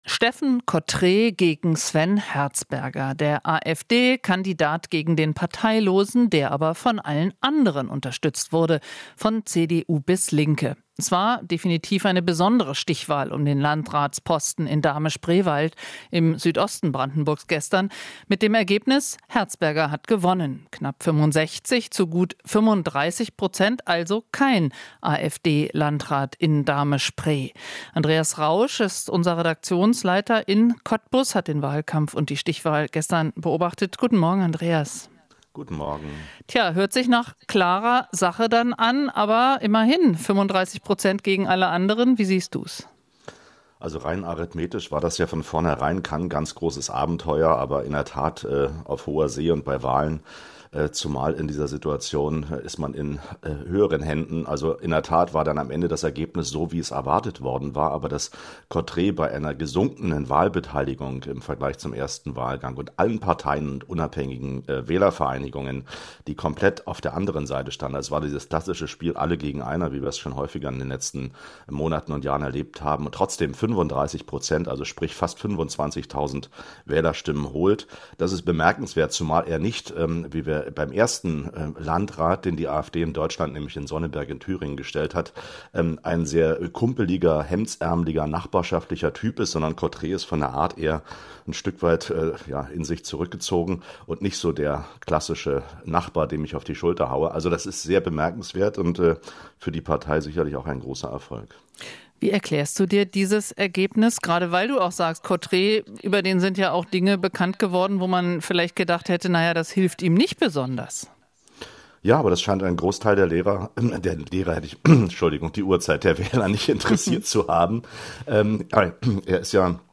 Interview - Herzberger (parteilos) wird Landrat im Kreis Dahme-Spreewald